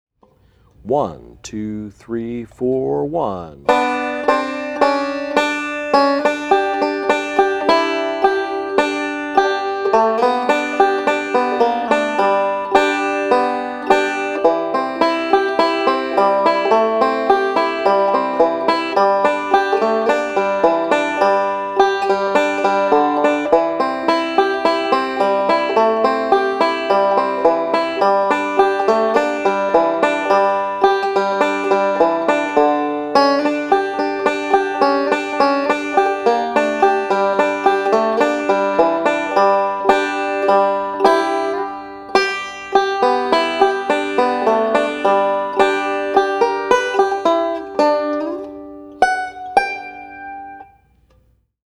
Voicing: Banjo Method